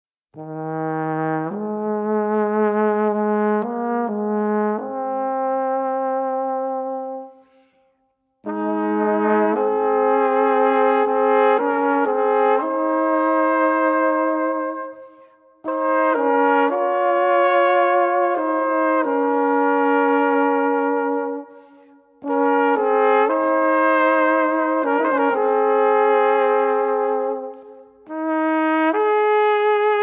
Gattung: 10 Weisen und Duette für verschiedene Stimmen
Besetzung: VOLKSMUSIK Weisenbläser